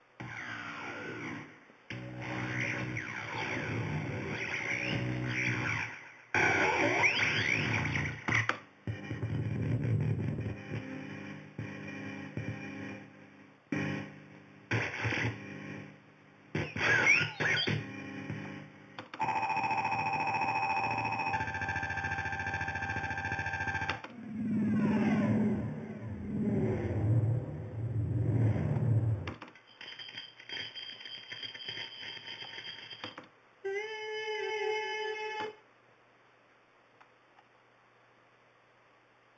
Oscillator
63883-oscillator.mp3